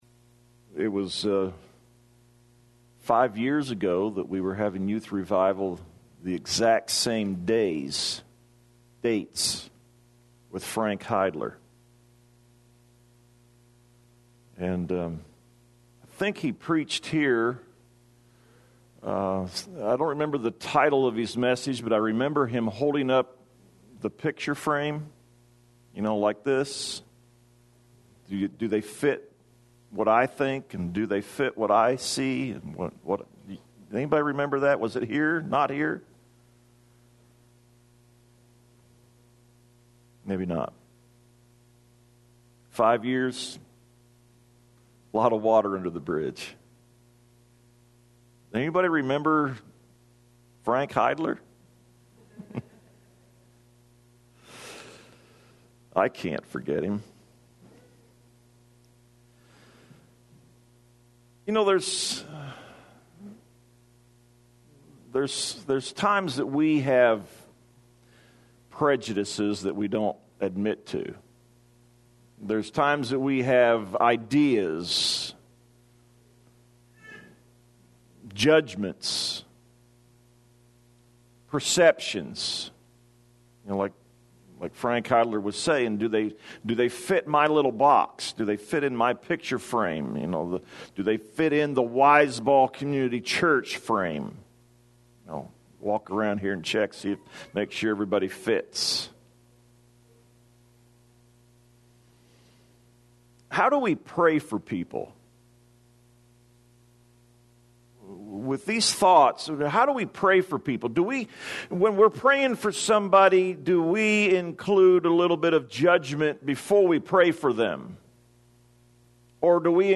Judging
sermon